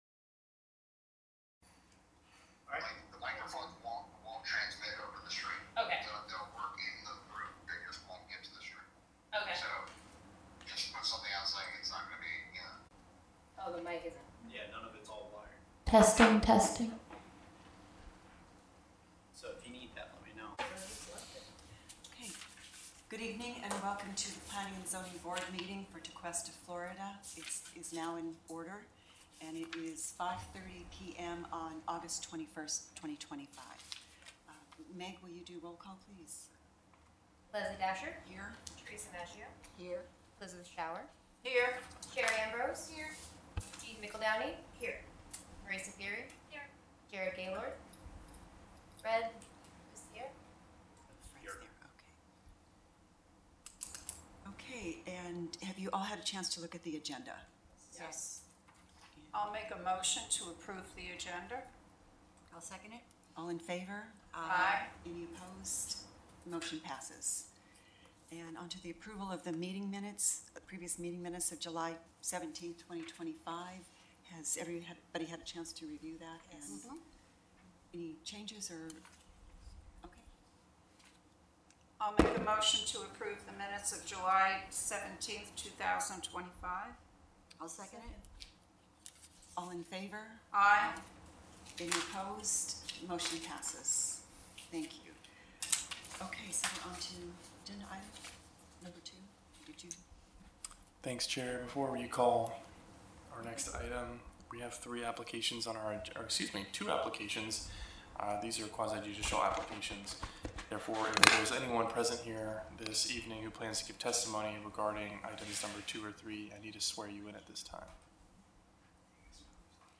You can access the meeting agenda and backup or watch the Planning and Zoning Board Meeting via live stream.